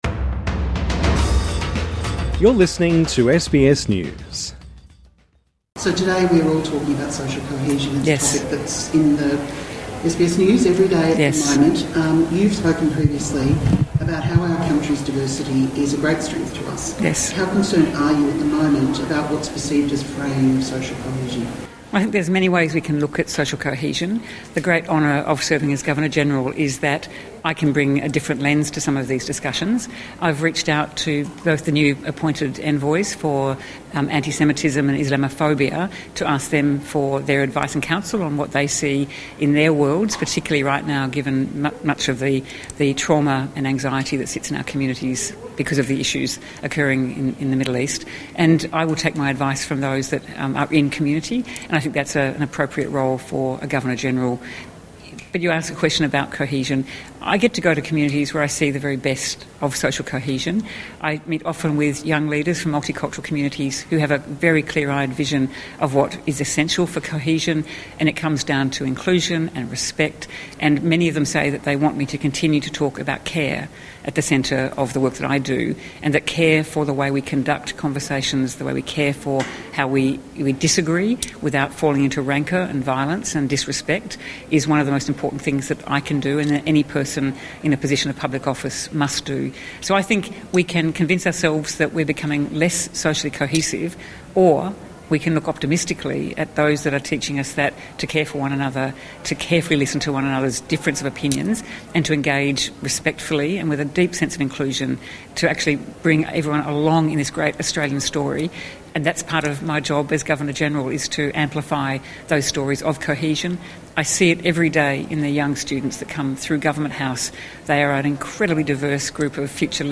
INTERVIEW Governor General Sam Mostyn on the upcoming royal visit